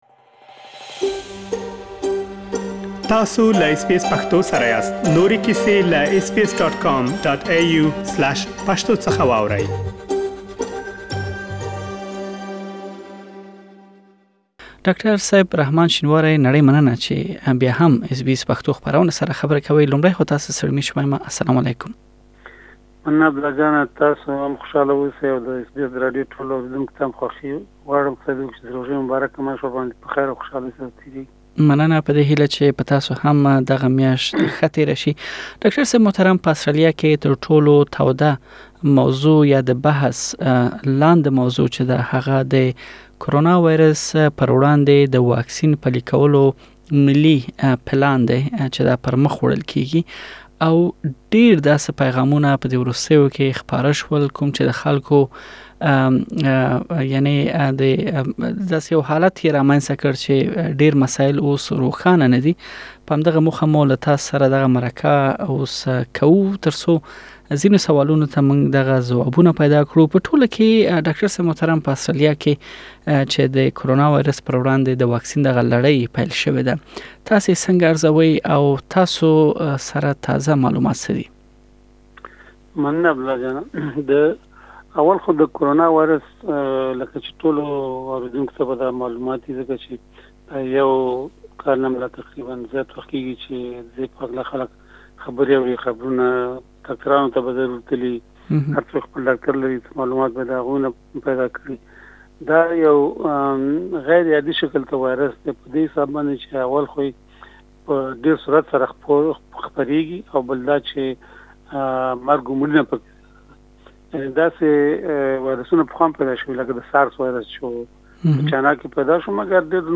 له ښاغلي سره مو د واکسين چارې او هم وينې لخته کيدو په اړه مرکه کړې، چې بشپړه مرکه دلته اوريدلی شئ.